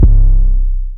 808s
(SXJ) 808 (1)_2.wav